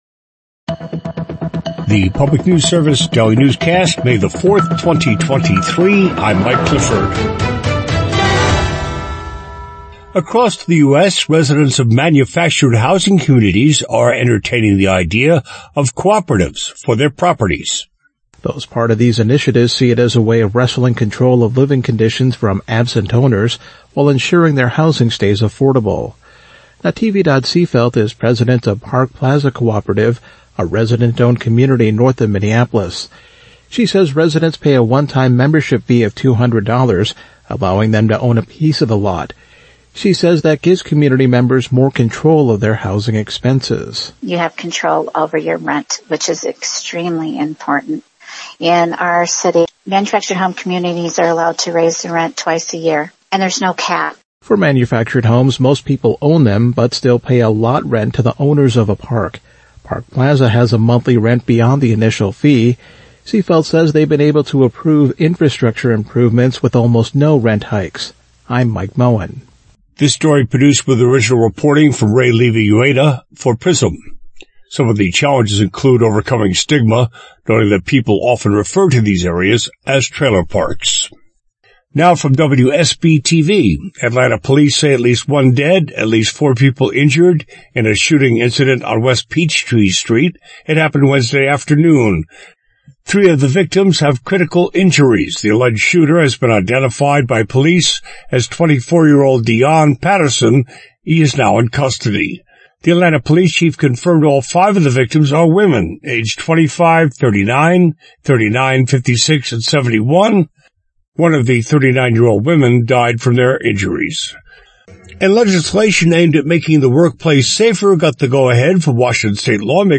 Afternoon news update for Wednesday, January 14, 2026